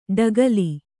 ♪ ḍagali